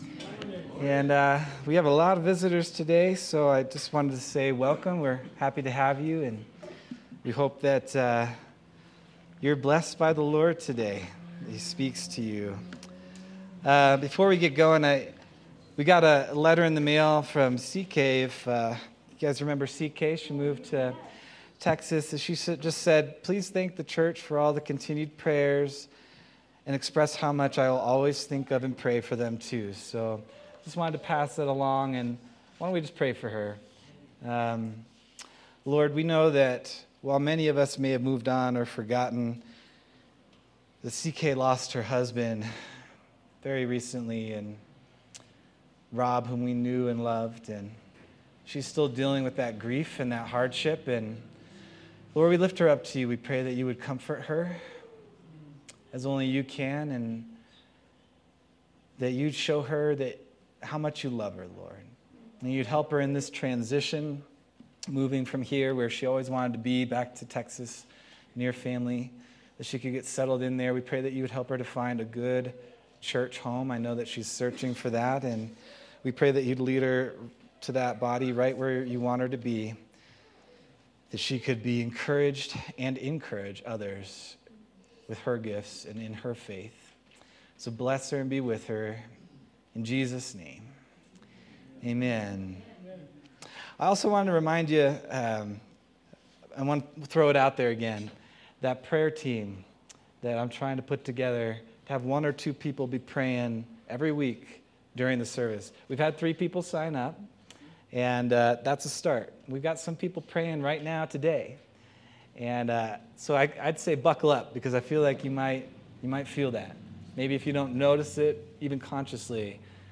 July 20th, 2025 Sermon